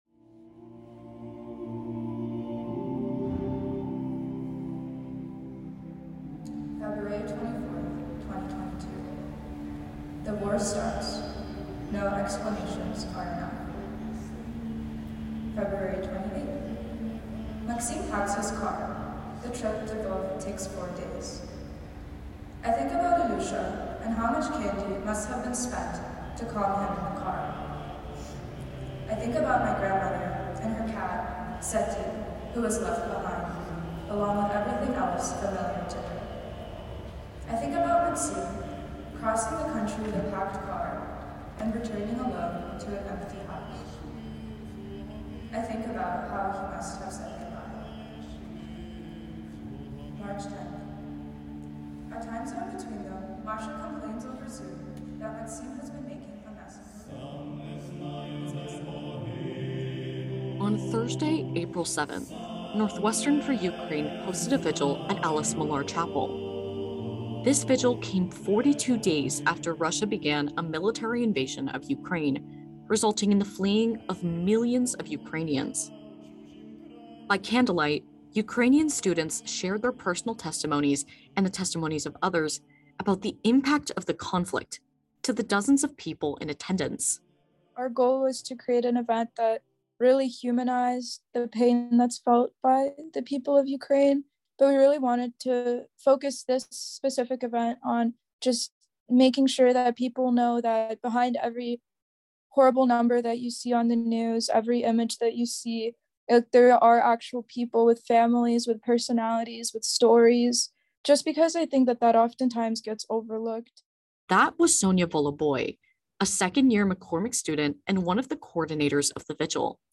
Ukrainian students share stories and raise awareness at candlelight vigil by NU for Ukraine